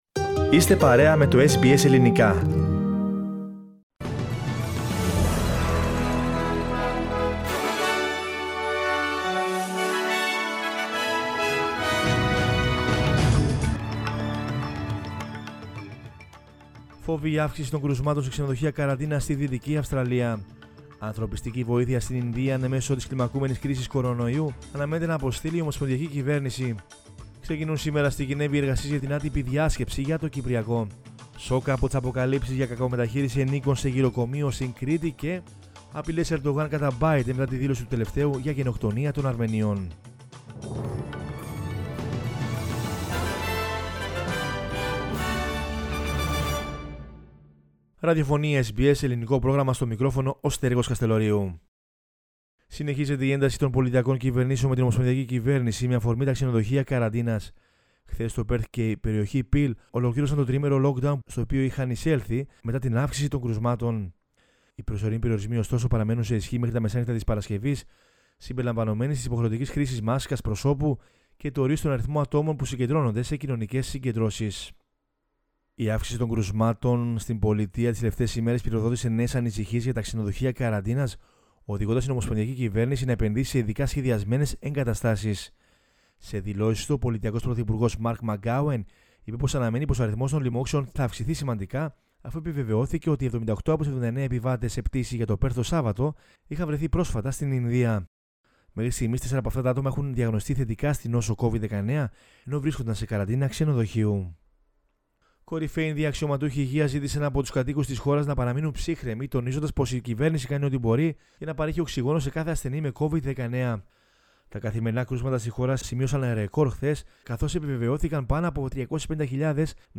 News in Greek from Australia, Greece, Cyprus and the world is the news bulletin of Tuesday 27 April 2021.